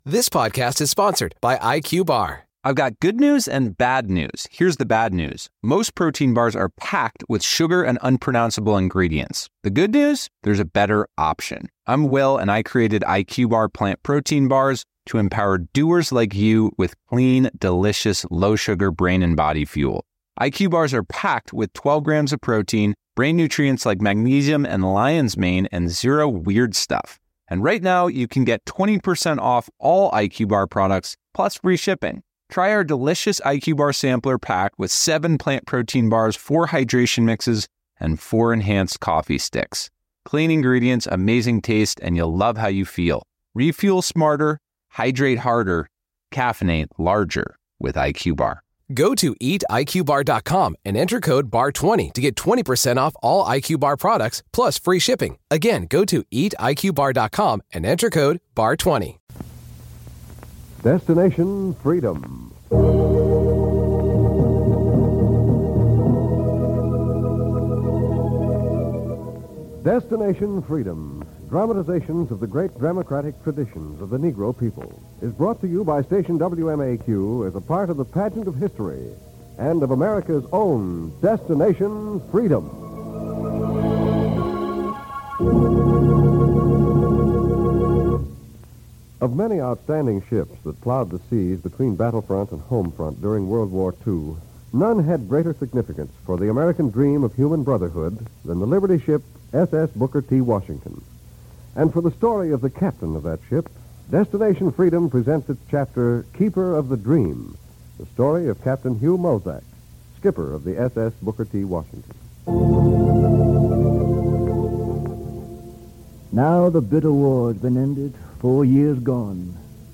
"Keeper of the Dream" is an episode from the "Destination Freedom" series that aired on October 30, 1949. This series was known for its dramatizations of the lives and struggles of notable African Americans, highlighting their contributions to society and the fight against racial discrimination.